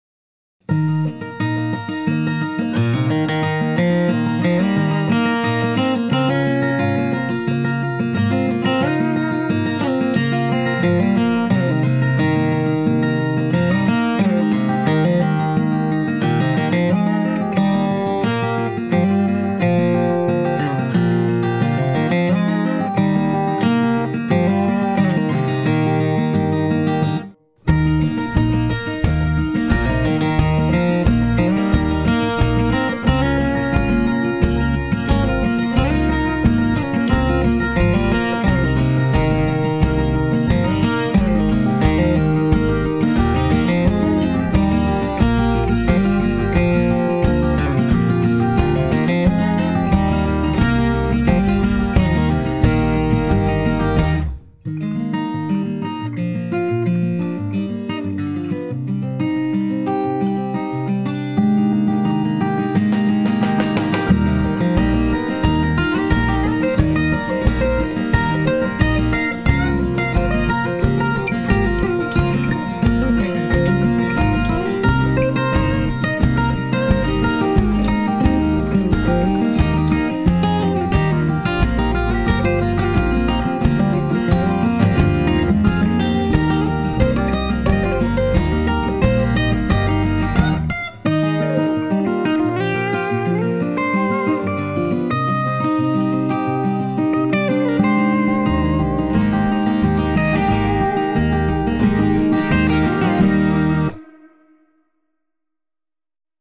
) features Martin OM-28 Marquis lead guitar crushed by DIY La2a Compressor